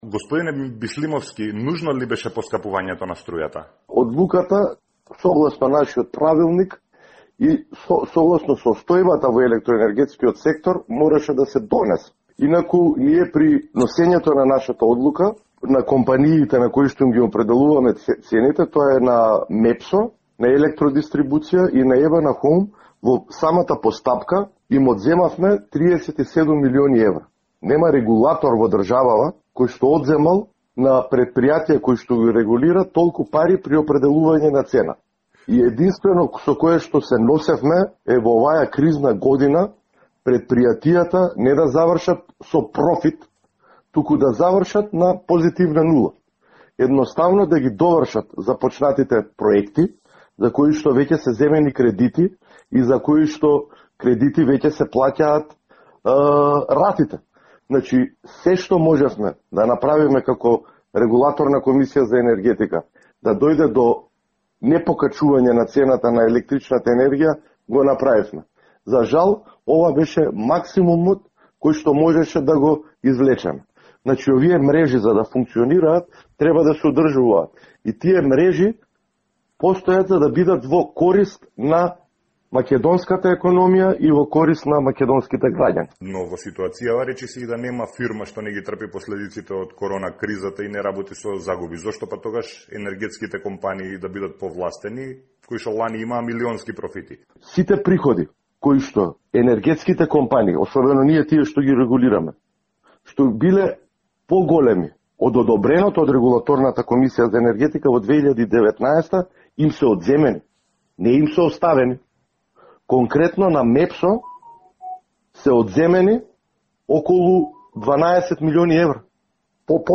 Исто така тие фотелји што ги зборат од телешки кожи и тие не се земени во предвид, ништо не е земенo од тоа што се шпекулира во јавноста при носењето на овие одлуки за поскапувањето на струјата, вели претседателот на Регулаторната комисија за енергетика Марко Бислимовски во интервју за Радио Слободна Европа.